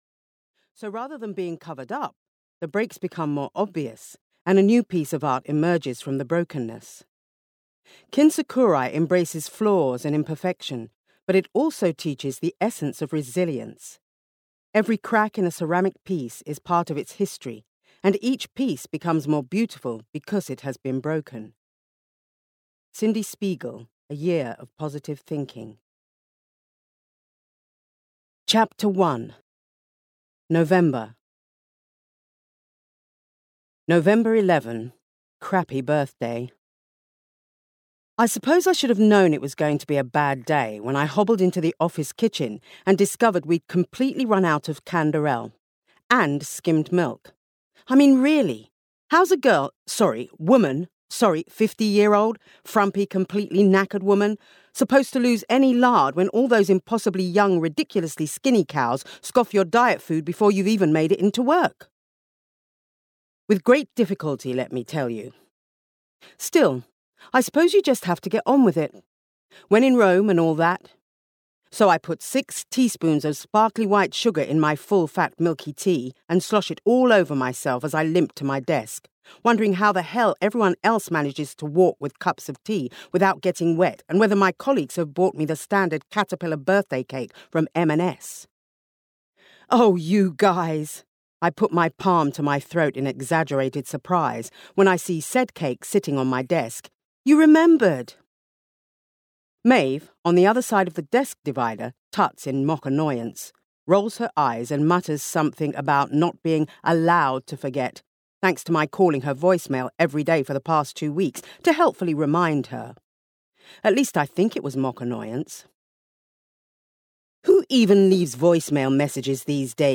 Annie Beaton's Year of Positive Thinking (EN) audiokniha
Ukázka z knihy